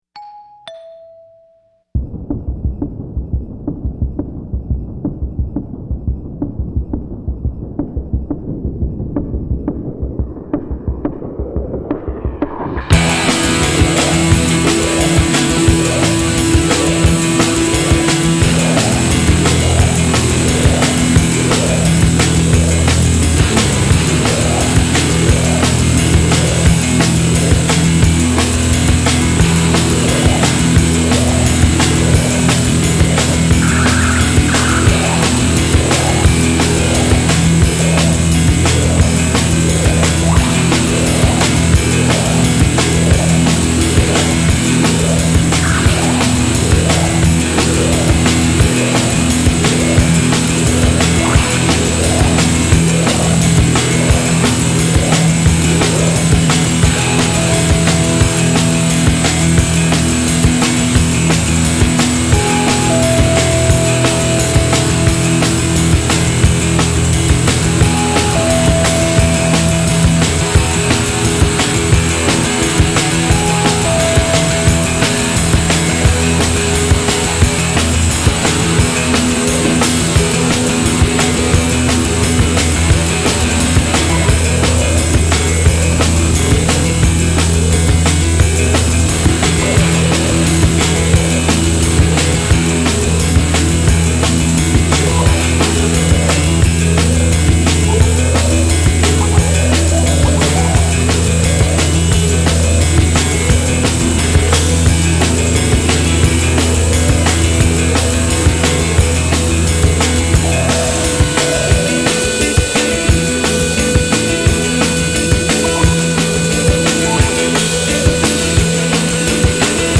-the cut&paste electronic techniques
-the use of moog and other keyboards
-the interaction between electronics and guitars
-the whimsical-ish vocals